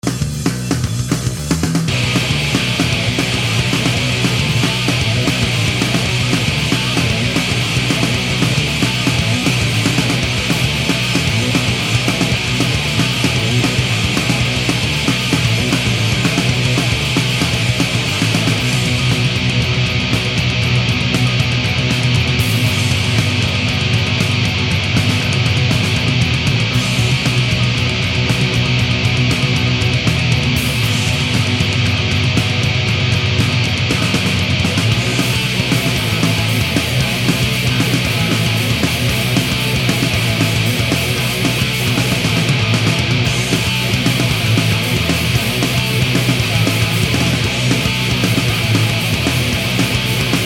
et voici encore un exemple de mix après nettoyage (il n'y a que de l'EQ)...on pourrait nettement améliorer avec un compresseur multibande et en améliorant le mix du playback (que je n'ai que sous forme de stéréo prémixé....)
gratte_mix.mp3